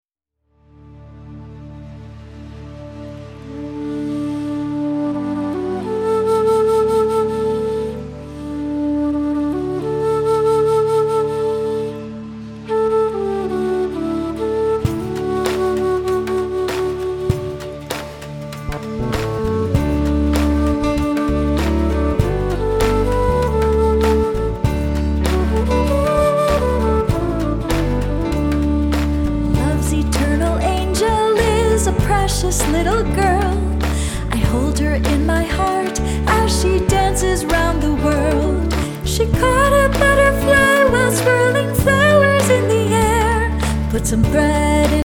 new concept in children's music